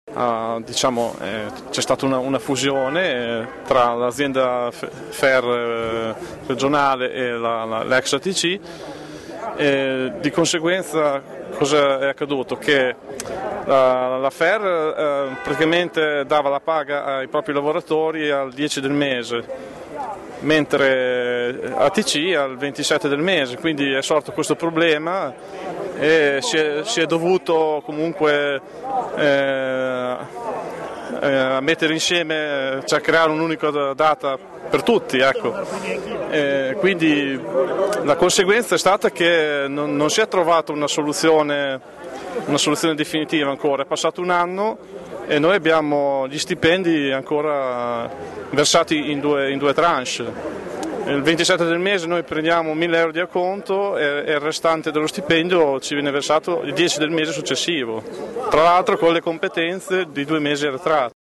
tranviere da 10 anni